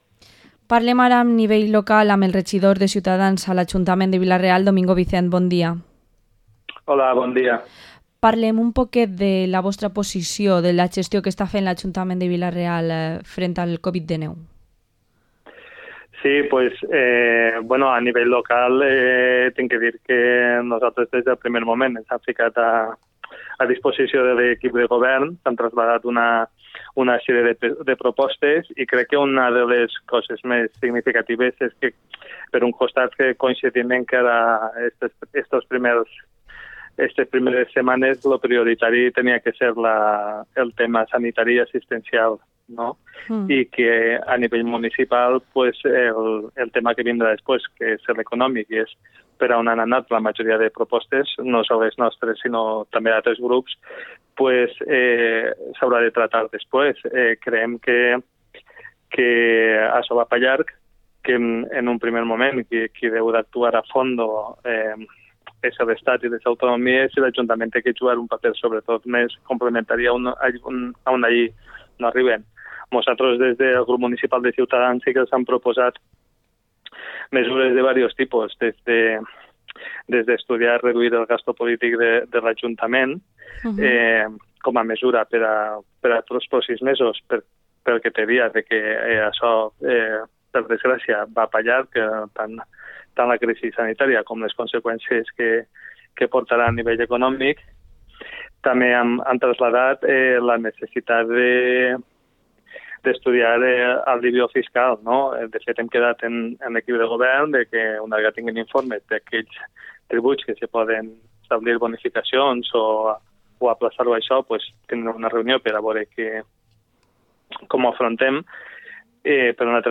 Entrevista a Domingo Vicent, concejal de Ciudadanos en Vila-real